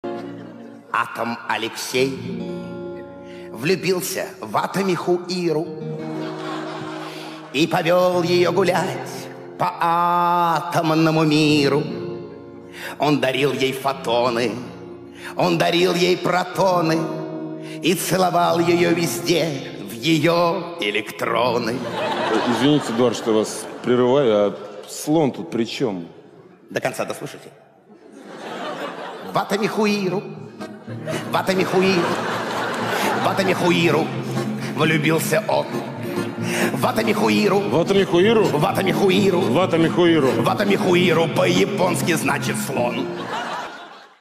• Качество: 192, Stereo
гитара
веселые
смешные